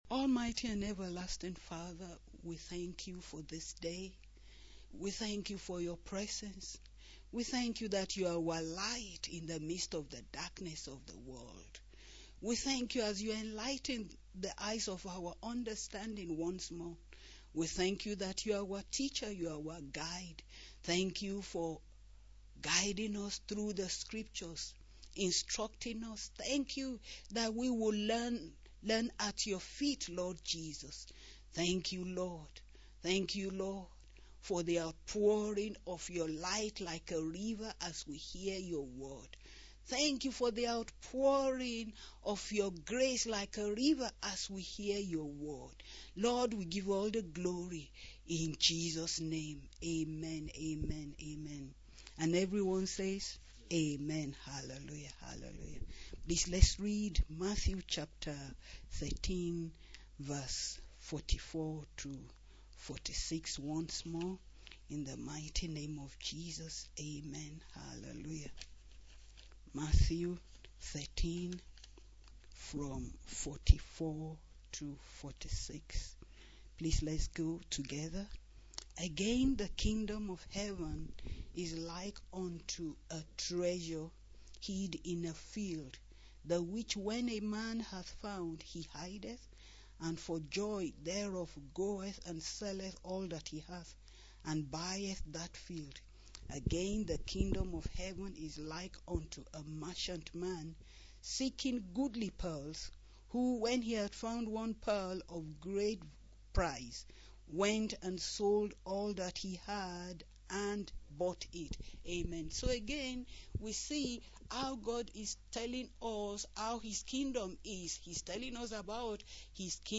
Proverbs 6 Verse 23 Sermon – Yeshua Christian Church
Proverbs 6 Verse 23 Sermon.mp3